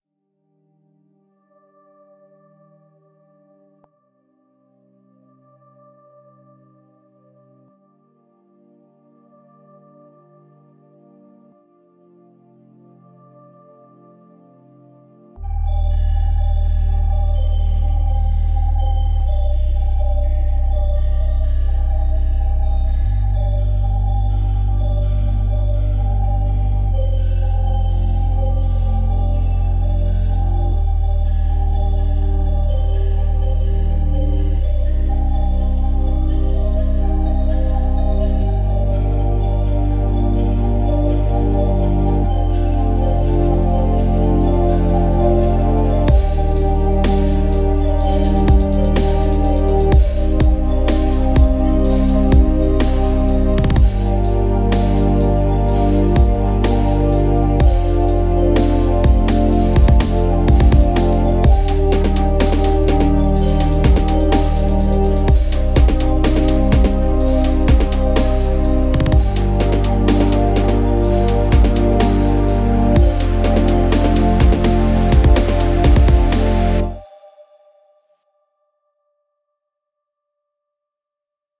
I started with the chords.
I had basic drums already in my first version, but I decided to do more with it.